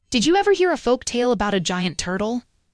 In fact, in some cases, the tone is completely off (for example, my voice).